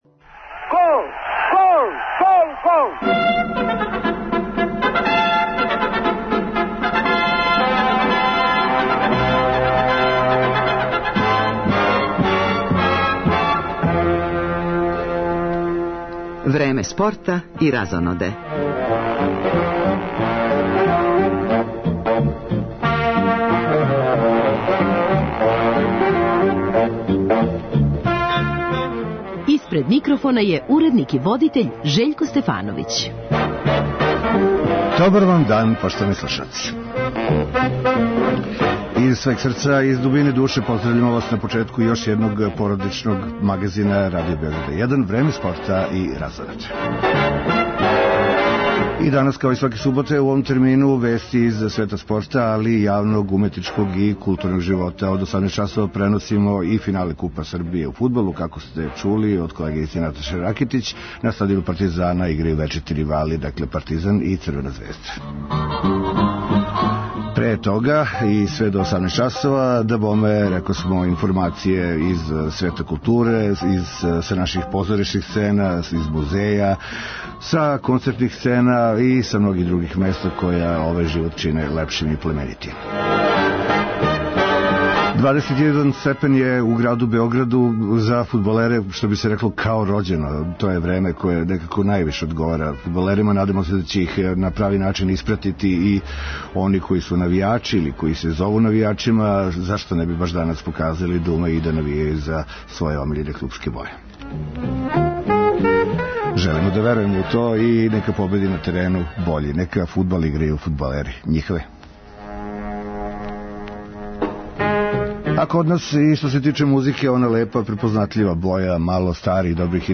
Како у наставку програма, преносимо финале Купа Србије у фудбалу, између Партизана и Црвене звезде, од 18 часова, чућемо и размишљања играча и тренера оба клуба уочи важног дуела. Пратимо и кретање резултата на утакмицама последњег кола Прве фудбалске лиге Србије, као и учешће наших одбојкаша и одбојкашица, кошаркашица, тенисера, веслача и кајакаша на међународној сцени.